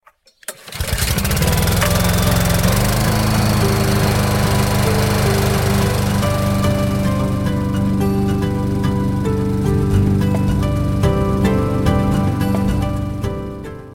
The churning and roaring motors of lawn care activities get old, not to mention the damage they can do to your hearing.
Hear the difference, listen to a yard work simulation below:
Yard Work Noise With and Without Plugfones
yardwork.mp3